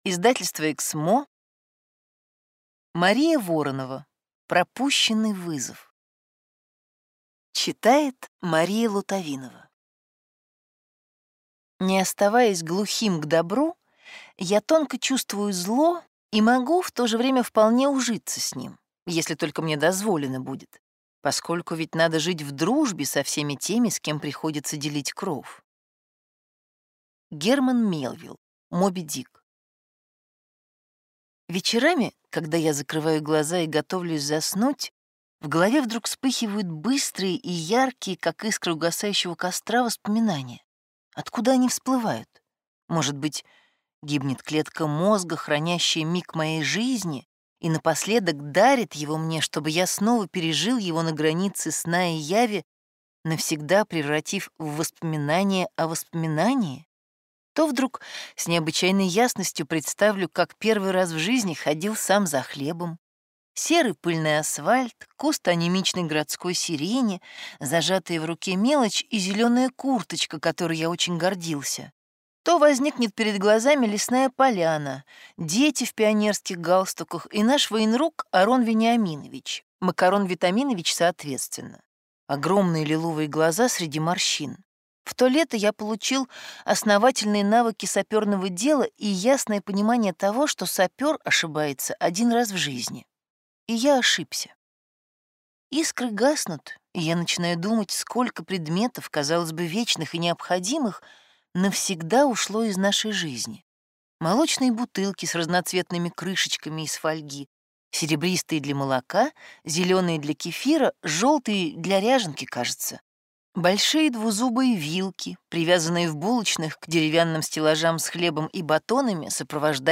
Аудиокнига Пропущенный вызов | Библиотека аудиокниг